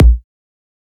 edm-kick-41.wav